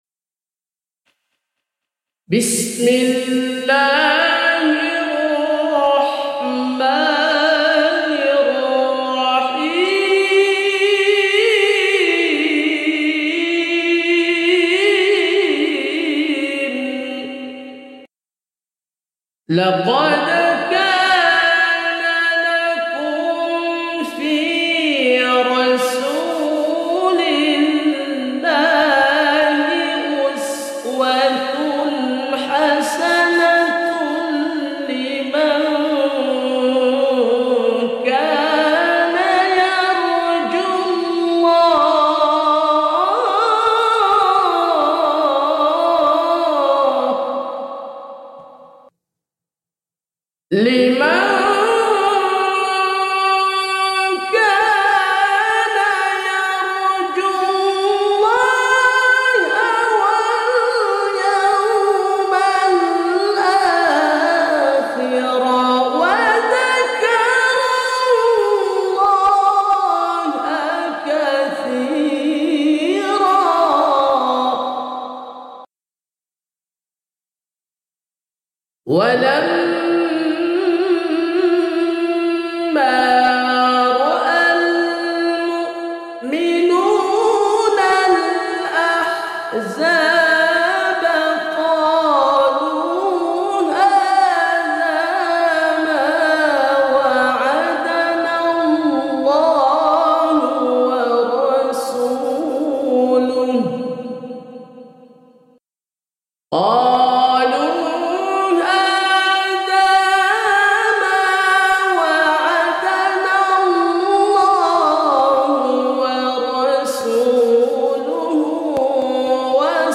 Tilawah Maqra Maulid